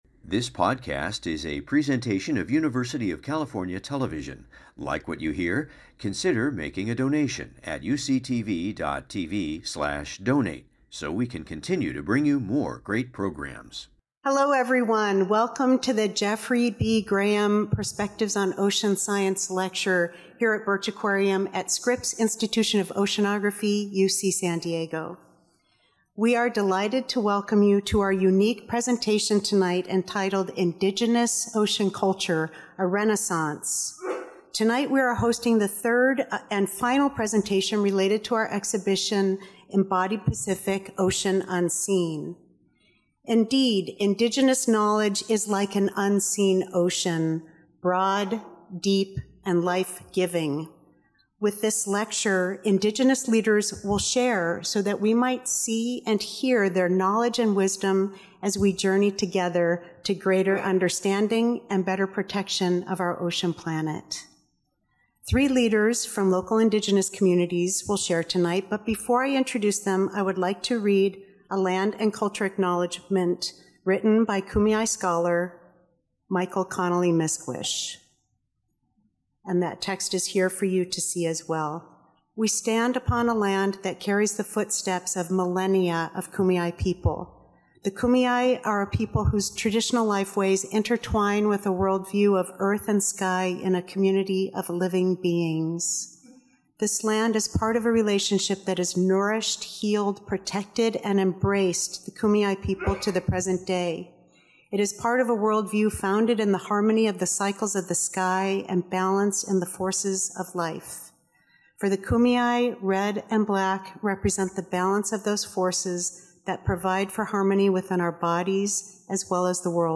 1 Our Common Climate: Variability and Climate Change in the U.S. - Mexico Western Border Region 53:12 Play Pause 4M ago 53:12 Play Pause Später Spielen Später Spielen Listen Gefällt mir Geliked 53:12 Please join us for the annual Keeling lecture in honor of Scripps Professor Charles David Keeling’s life and invaluable contributions to climate science and the Scripps Institution of Oceanography.